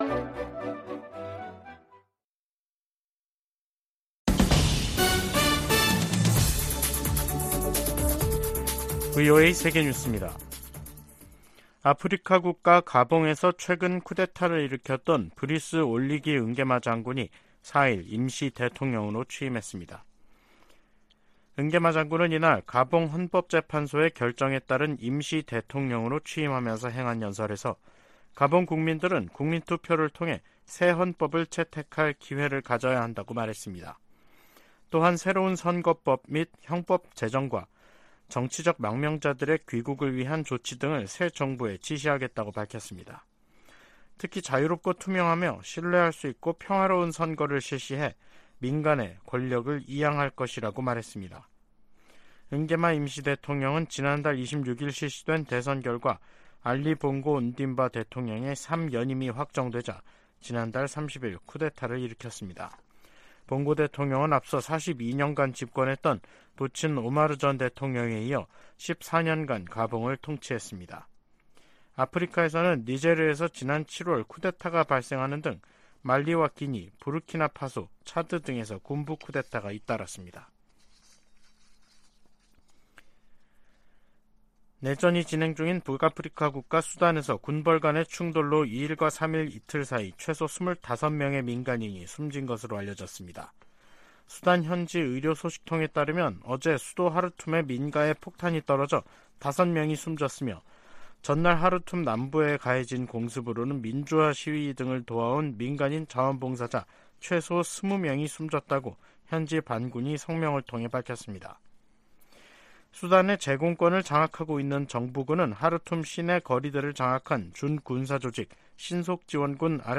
VOA 한국어 간판 뉴스 프로그램 '뉴스 투데이', 2023년 9월 4일 3부 방송입니다. 북한이 전략순항미사일을 발사하며 핵 공격 능력을 과시하려는 도발을 이어갔습니다. 러시아가 북한에 북중러 연합훈련을 공식 제의했다고 한국 국가정보원이 밝혔습니다. 백악관은 바이든 행정부가 인도태평양 지역을 중시하고 있다고 거듭 강조했습니다.